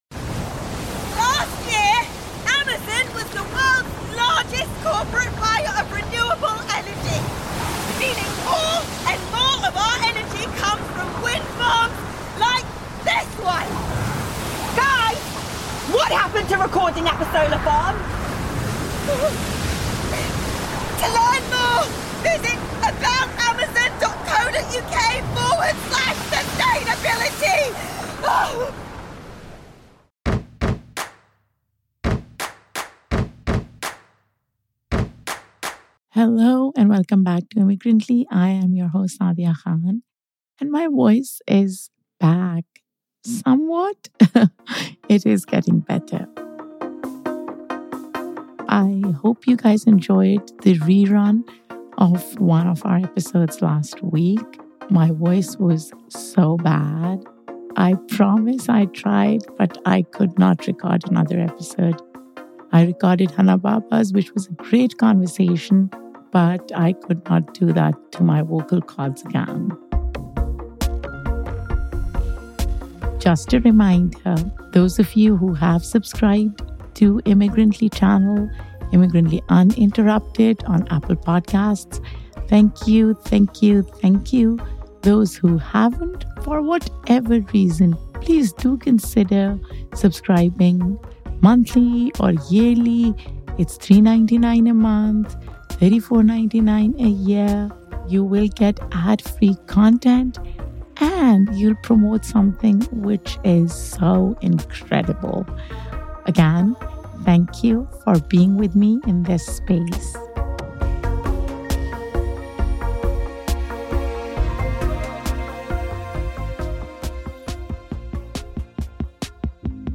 In this gripping conversation